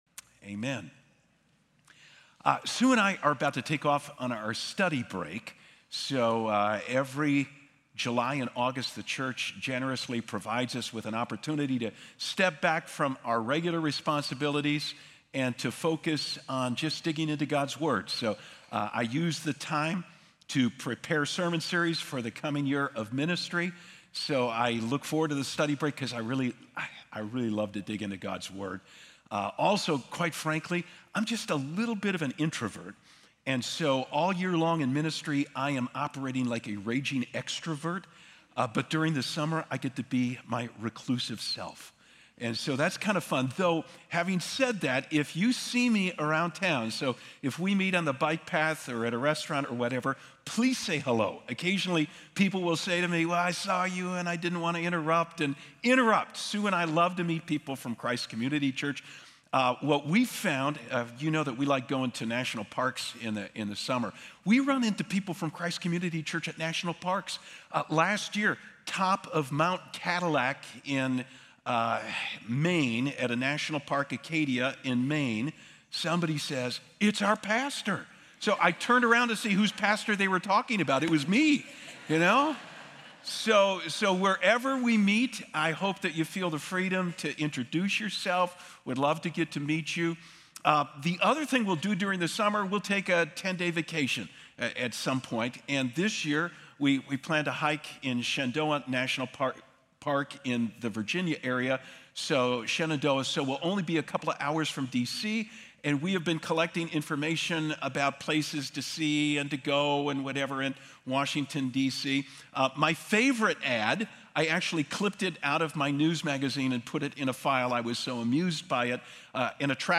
In the final sermon of the Heart Shapers series